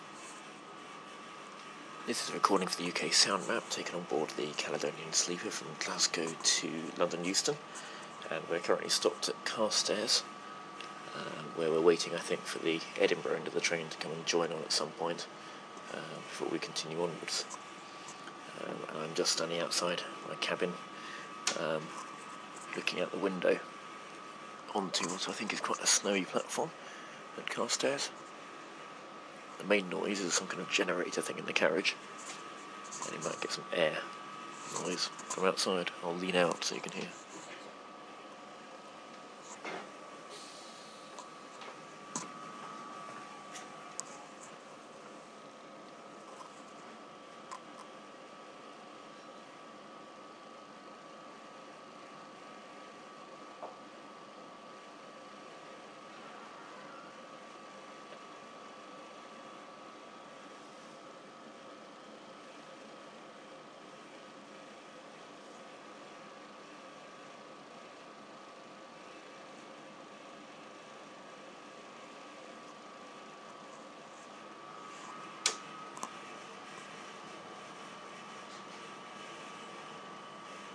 Caledonian sleeper at Carstairs - uksm